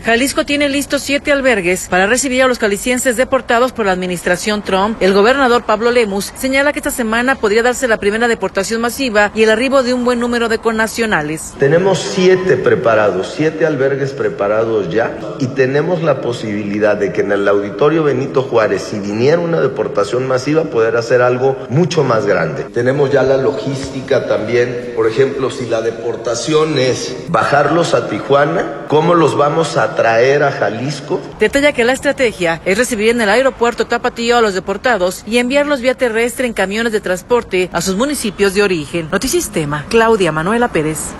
Jalisco tiene listos siete albergues para recibir a los jaliscienses deportados por la administración Trump. El gobernador Pablo Lemus señala que esta semana podría darse la primera deportación masiva y el arribo de un buen número de connacionales.